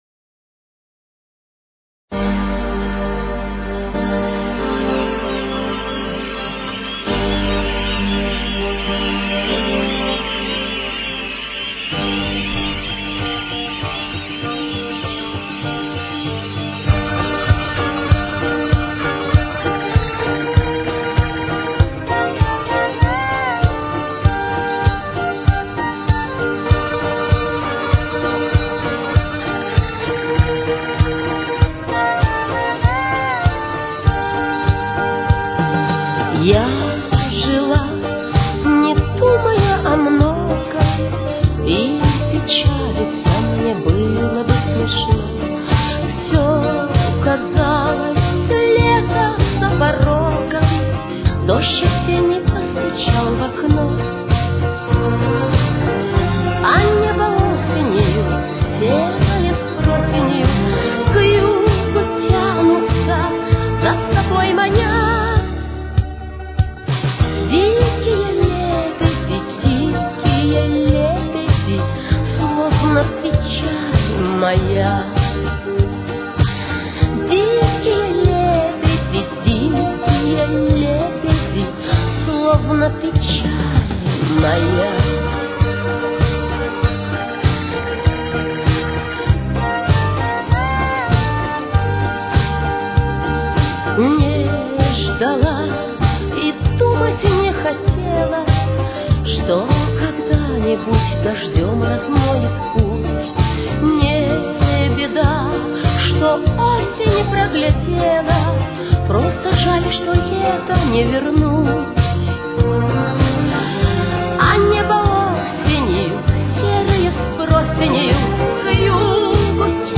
с очень низким качеством (16 – 32 кБит/с)
Соль минор. Темп: 100.